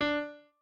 piano2_33.ogg